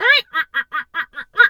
pgs/Assets/Audio/Animal_Impersonations/duck_2_quack_seq_03.wav at master
duck_2_quack_seq_03.wav